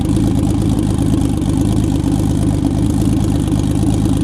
rr3-assets/files/.depot/audio/Vehicles/v10_f1/f1_idle.wav
f1_idle.wav